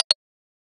switch_001.ogg